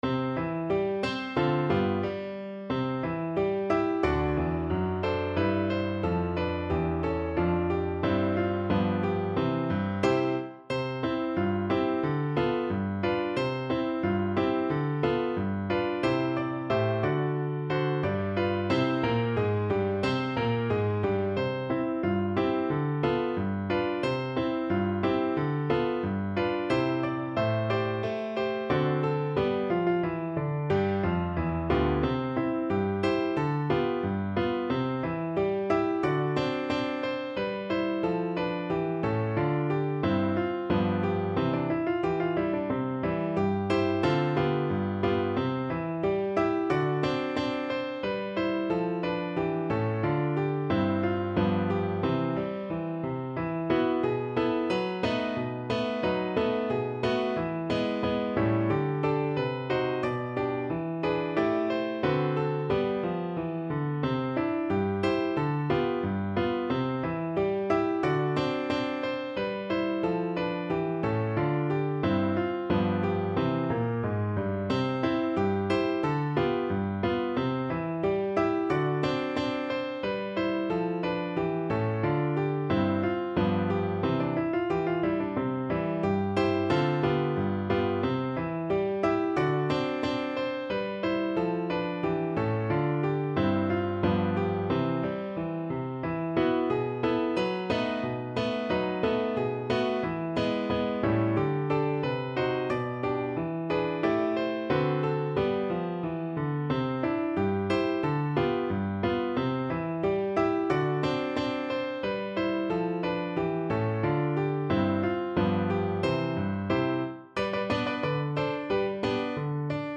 =90 Fast and cheerful
Pop (View more Pop Voice Music)